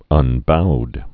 (ŭn-boud)